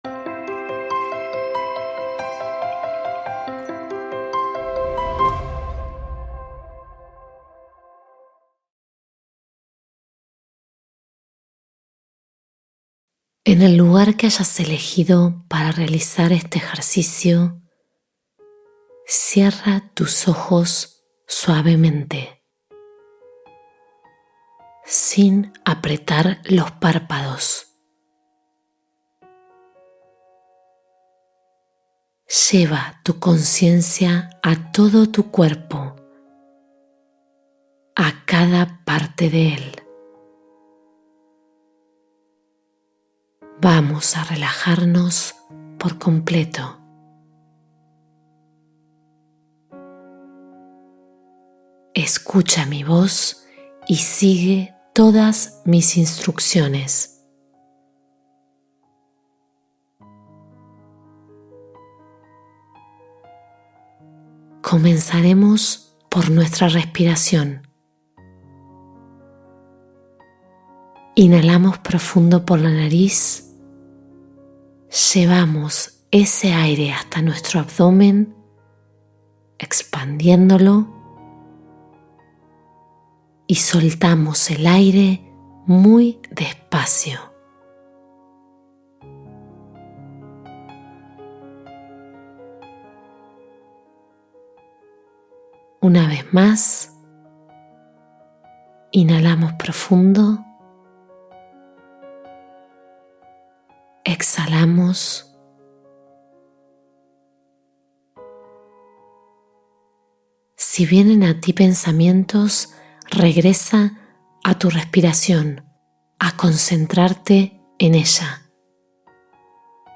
Vivir el Ahora sin Esfuerzo: Meditación de Atención Plena para Presencia Real